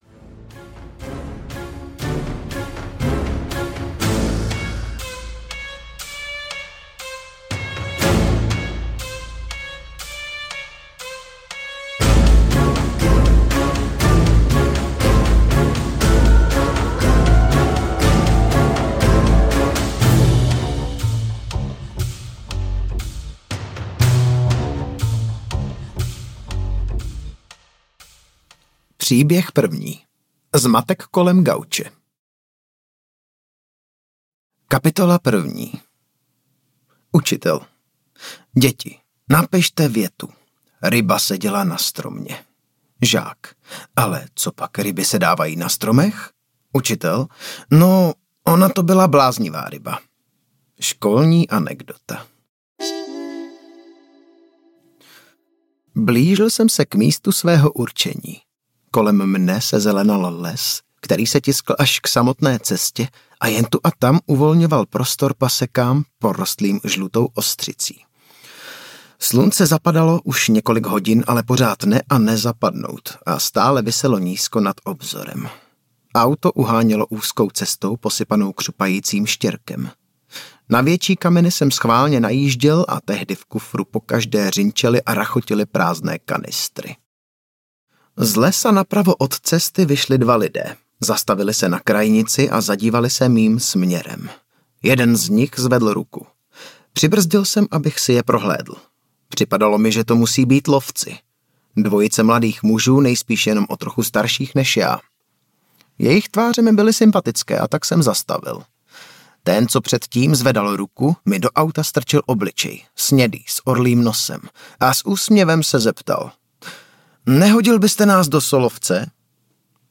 Pondělí začíná v sobotu audiokniha
Ukázka z knihy